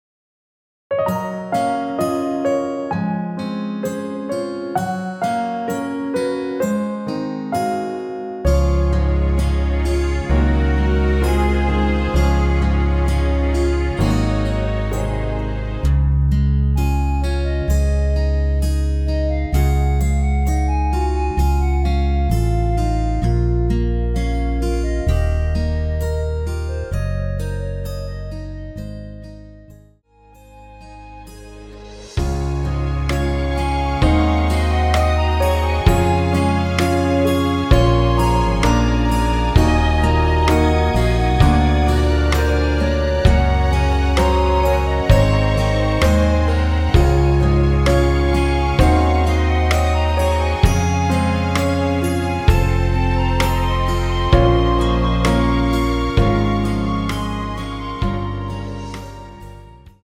여자키 멜로디 포함된 MR 입니다.(미리듣기 참조)
C#
앞부분30초, 뒷부분30초씩 편집해서 올려 드리고 있습니다.
중간에 음이 끈어지고 다시 나오는 이유는